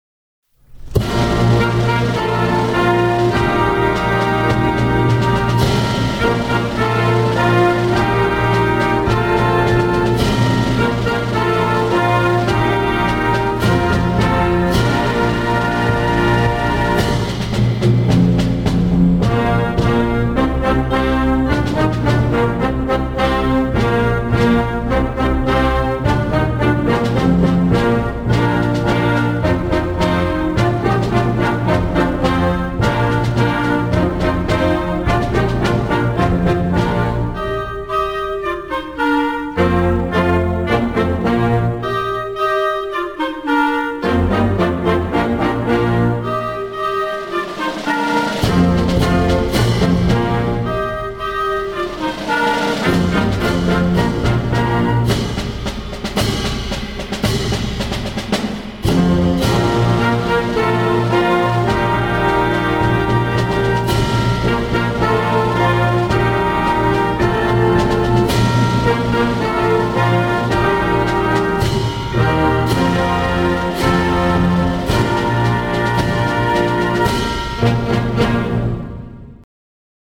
フレックス・バンド，アンサンブル曲の参考音源